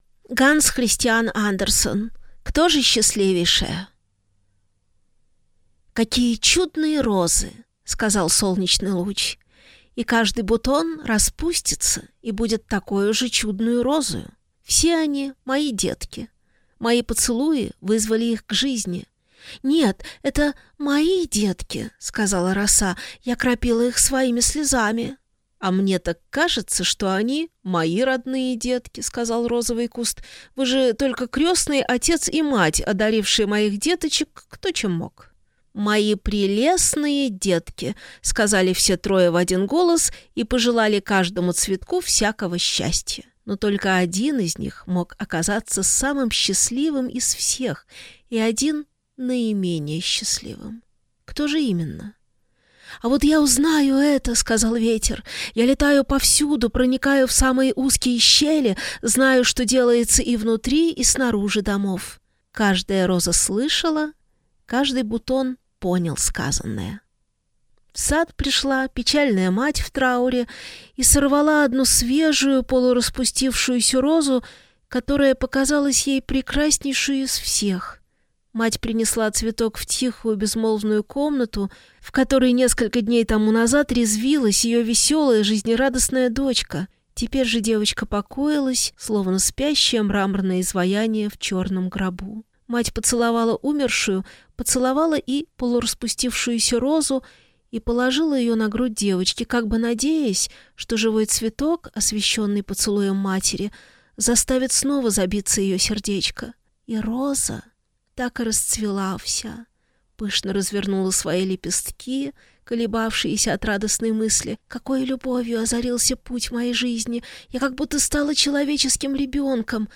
Аудиокнига Кто же счастливейшая? | Библиотека аудиокниг